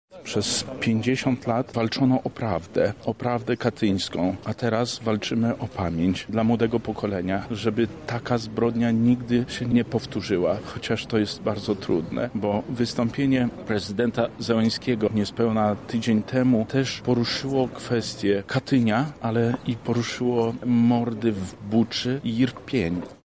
Czcijmy pamięć naszych bohaterów, oddając im należny hołd -mówi Zbigniew Wojciechowski, wicemarszałek województwa lubelskiego: